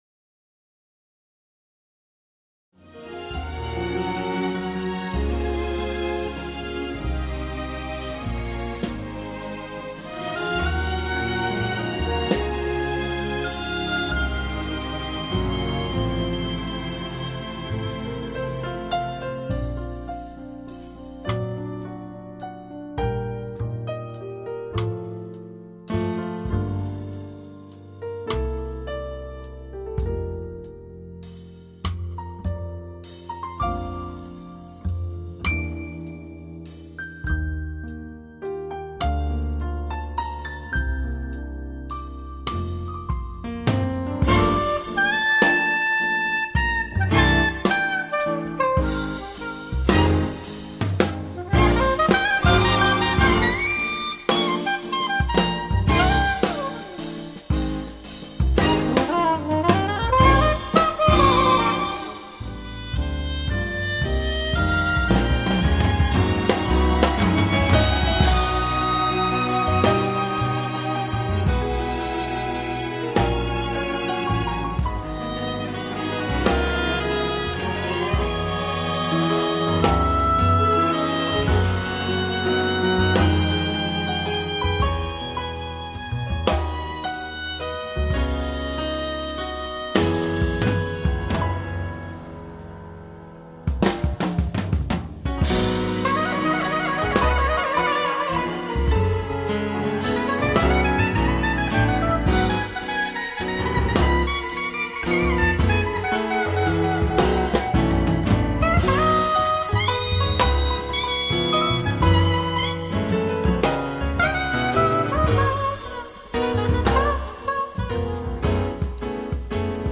piano
bass
sax
drums